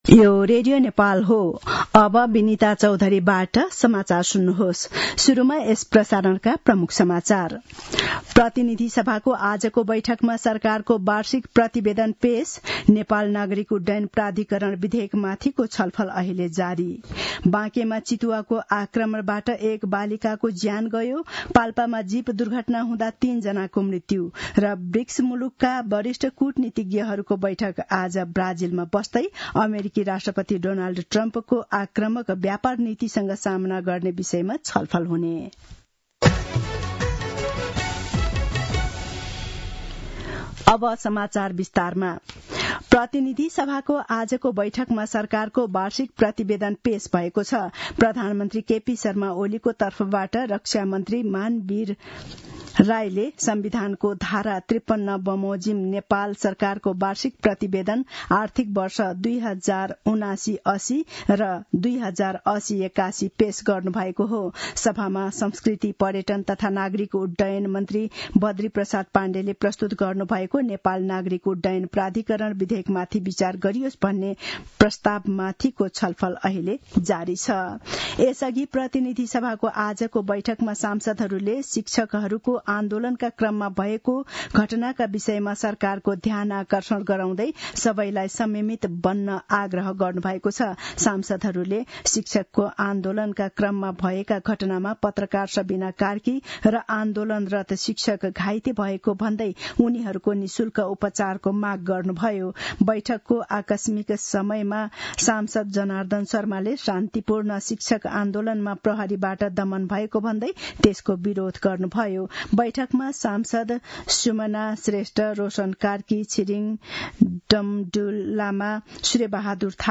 दिउँसो ३ बजेको नेपाली समाचार : १५ वैशाख , २०८२